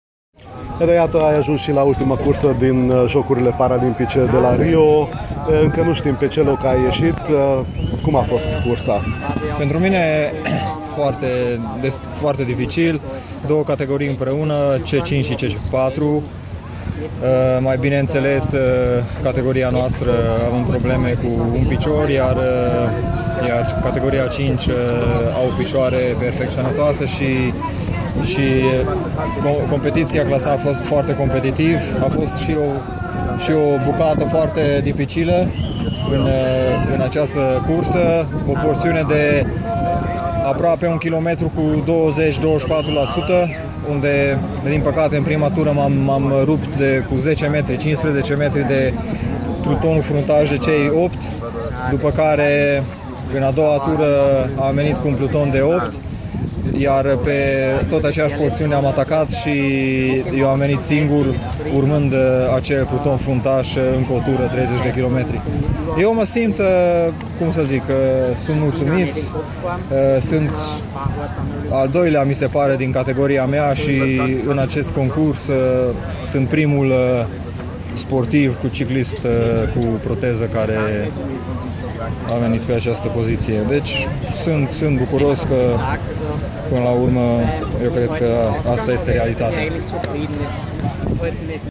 Un interviu audio cu ciclistul harghitean